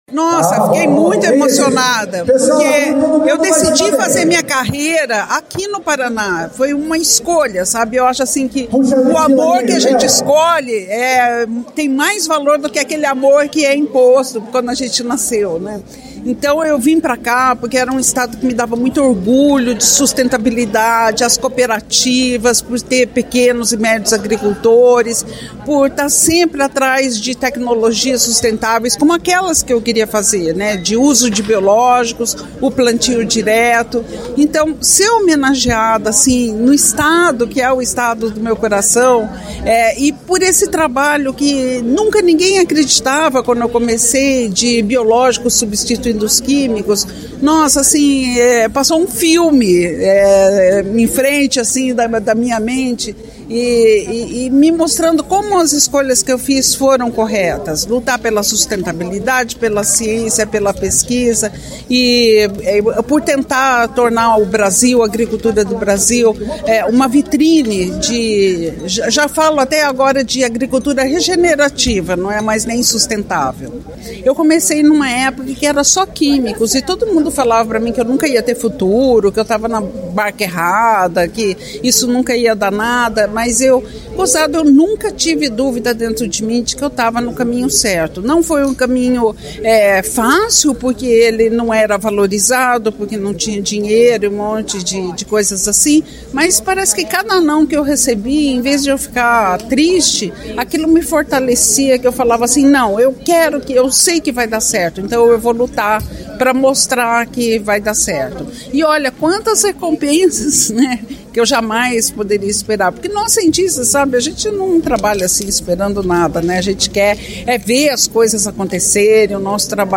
Sonora da engenheira agrônoma da Embrapa, Mariangela Hungria, sobre a Ordem do Pinheiro